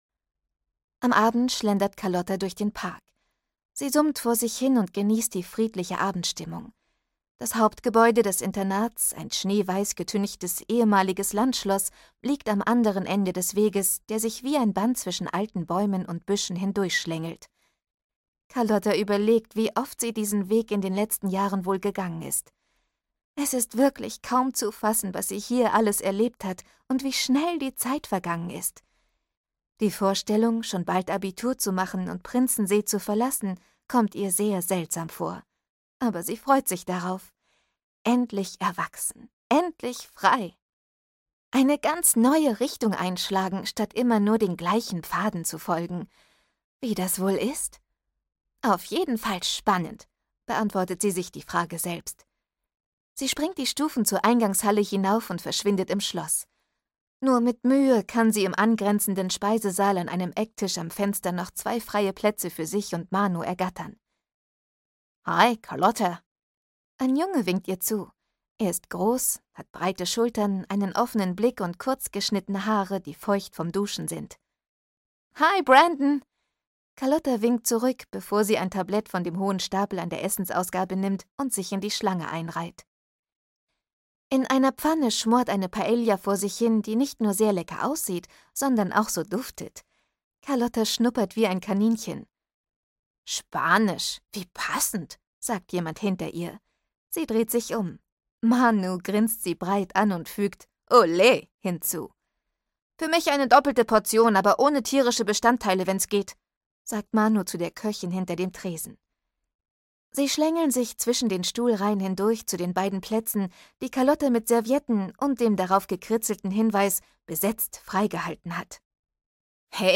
Carlotta 7: Carlotta - Internat auf Klassenfahrt - Dagmar Hoßfeld - Hörbuch